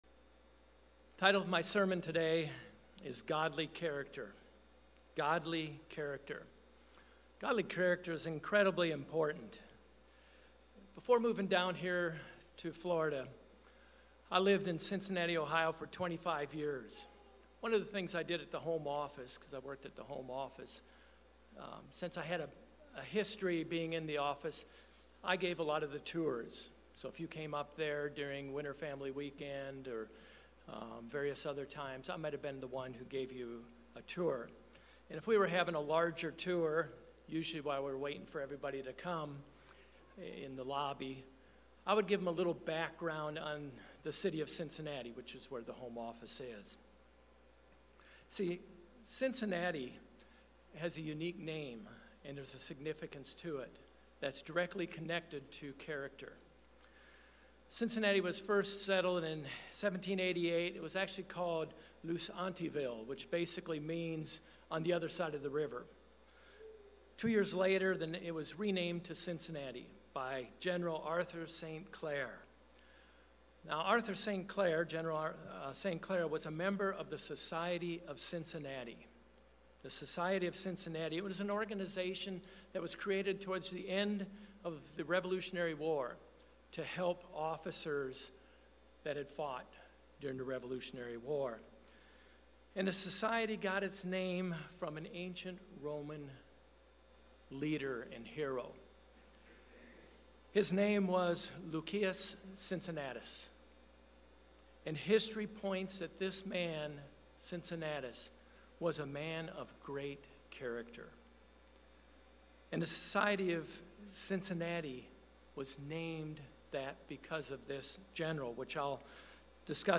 Building godly character is very important in our Christian life. In this sermon we review several points in building godly character.